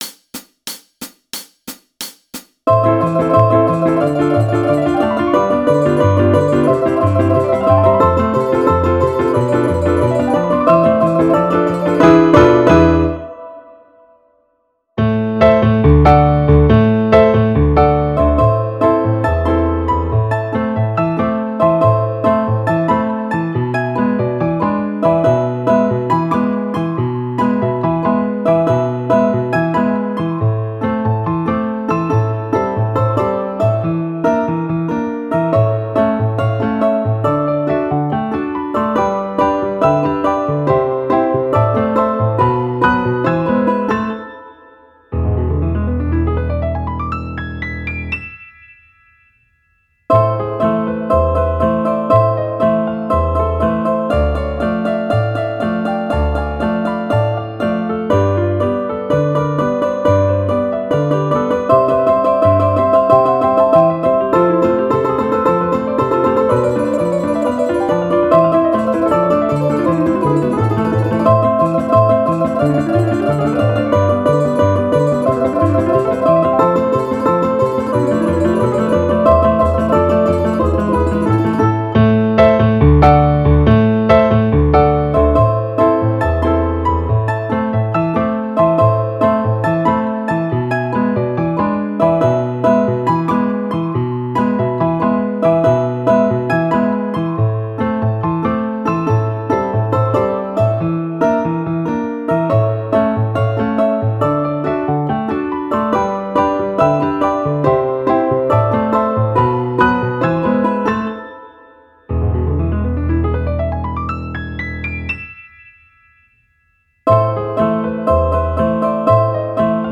Ансамбли